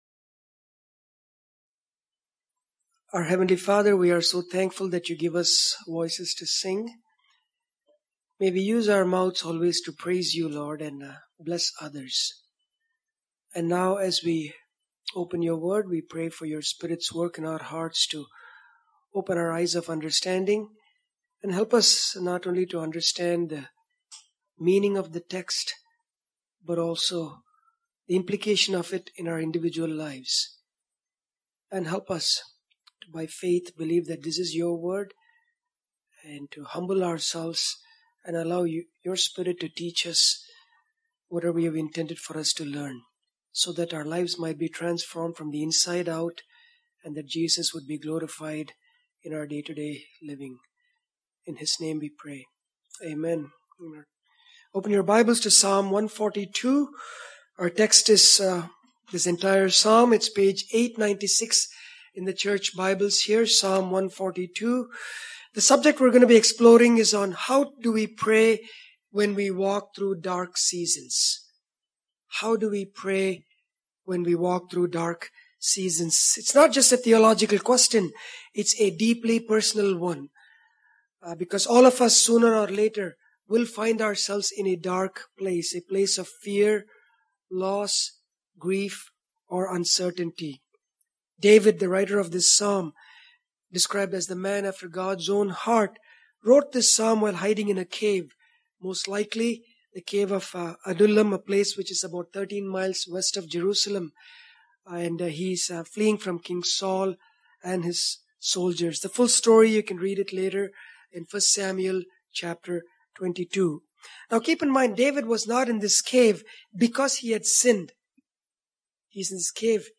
Psalm 142 isn’t just David’s cry from a cave — it’s God’s guide for us in our own moments of despair. In this sermon, we explore four powerful ways David prayed that we can imitate when life feels overwhelming.